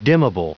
Prononciation du mot dimmable en anglais (fichier audio)
Prononciation du mot : dimmable